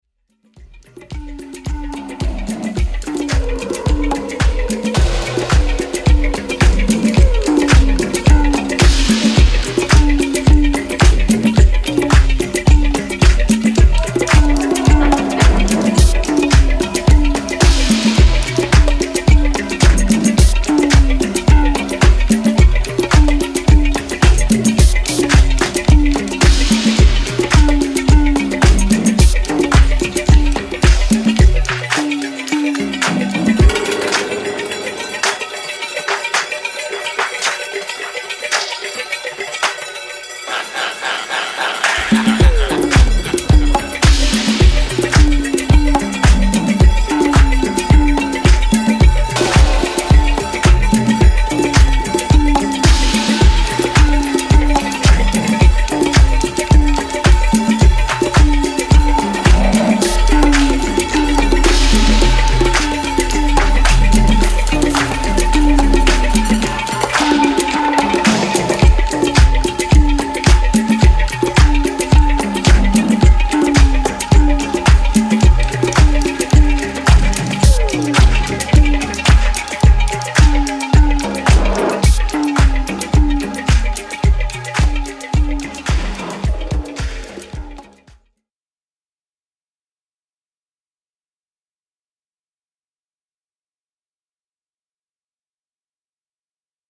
109 BPM